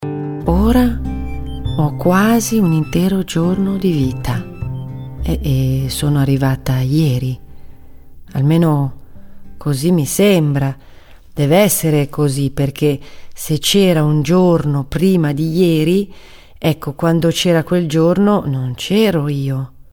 Аудиокниги на итальянском языке - Audiobooks